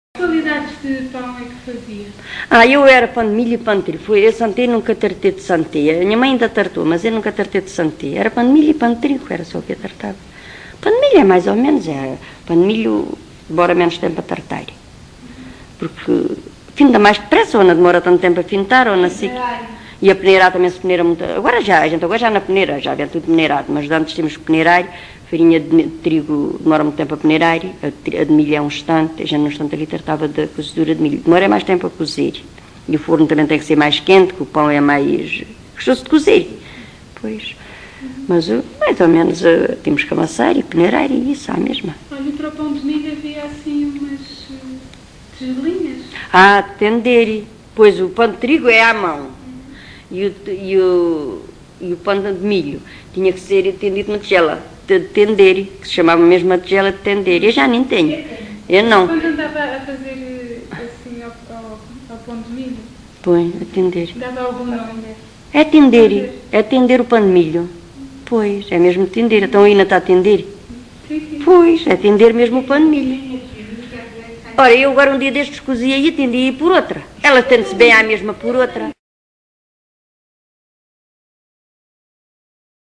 LocalidadeSanta Justa (Coruche, Santarém)